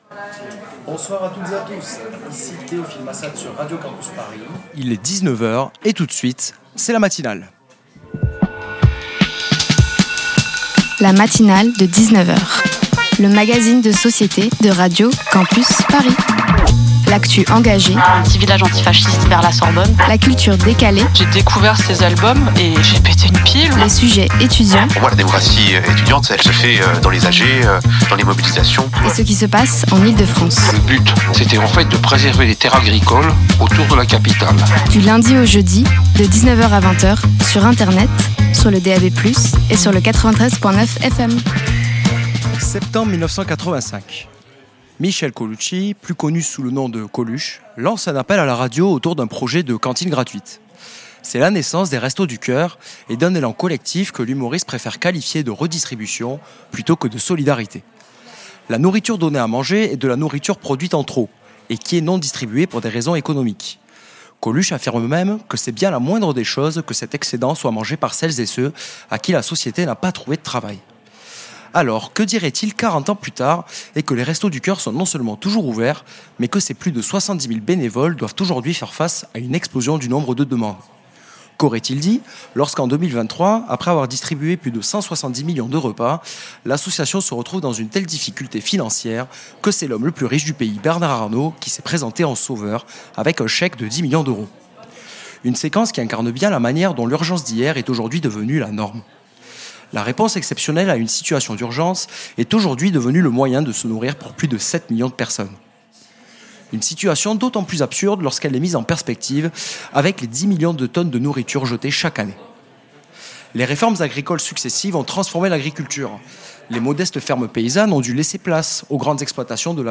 En public : Sécurité sociale de l'alimentation – à...
Une matinale spéciale autour de la sécurité sociale de l'alimentation en format "hors les murs" en direct du restaurant étudiant solidaire La Cop1ne.